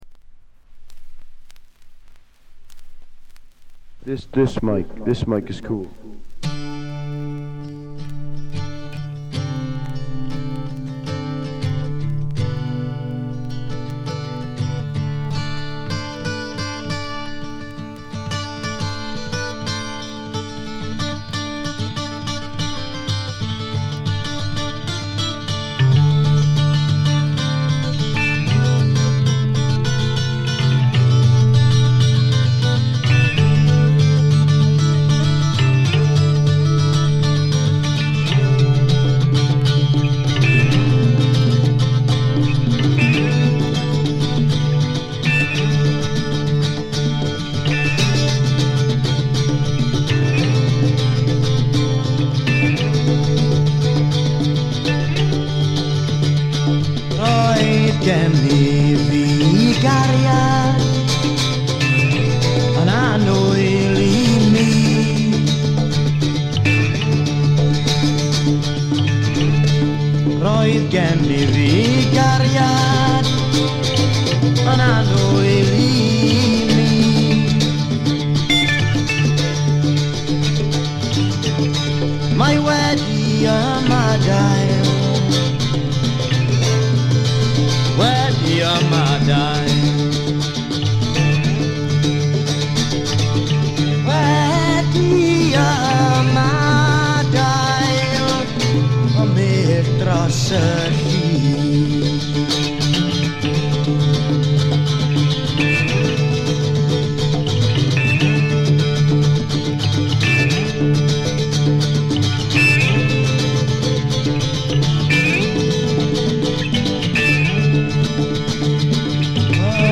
わずかなノイズ感のみ。
内容的にはおそろしく生々しいむき出しの歌が聞こえてきて、アシッド・フォーク指数が異常に高いです。
試聴曲は現品からの取り込み音源です。
Vocals, Acoustic Guitar